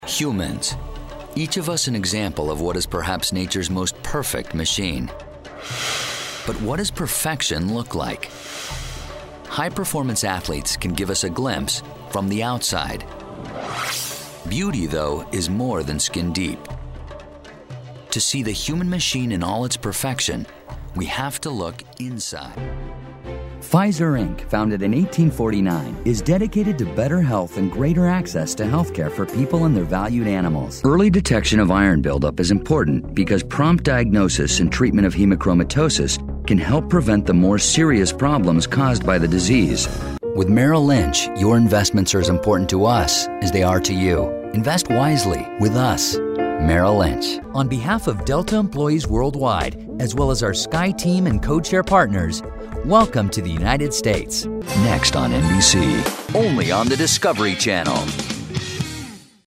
Top Bilingual Voice Over Talent in English and Spanish that has recorded hundreds of commercials, promos, narrations, corporate videos, and other project for Fortune 500 companies around the globe.
Sprechprobe: Sonstiges (Muttersprache):